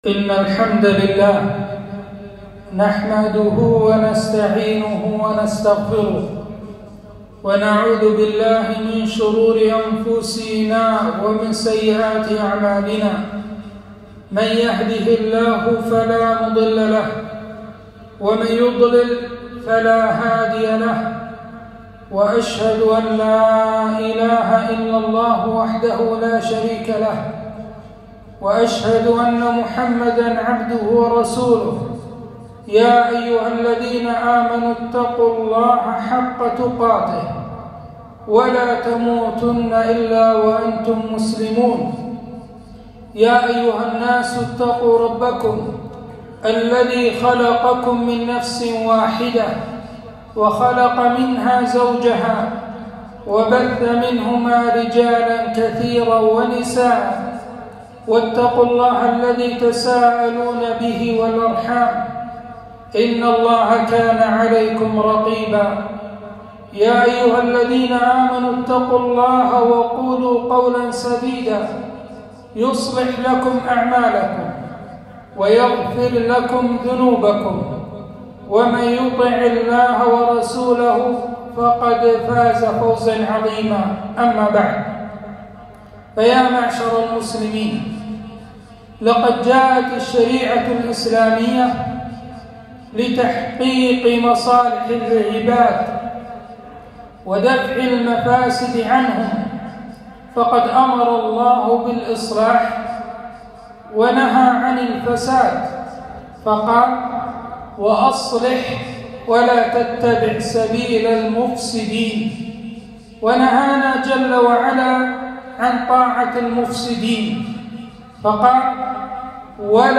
خطبة - إن الله لايصلح عمل المفسدين